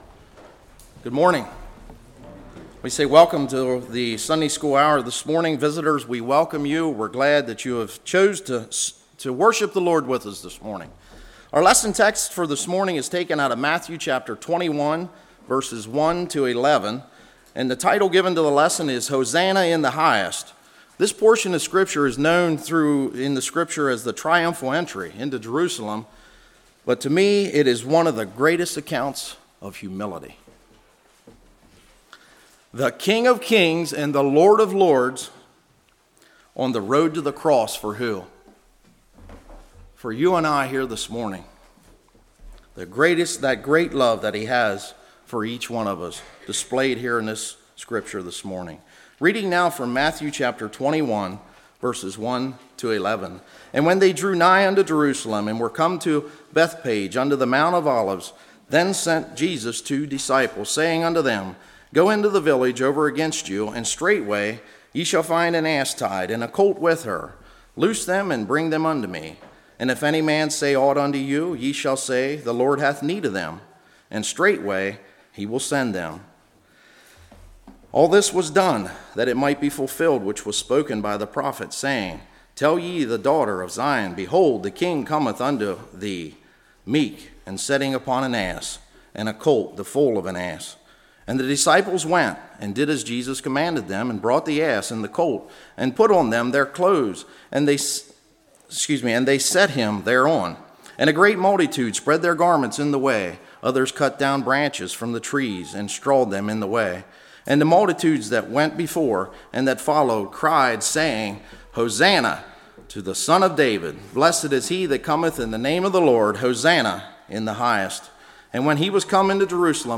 Matthew 21:1-11 Service Type: Sunday School The Unbroken Colt The Disciples Obeyed.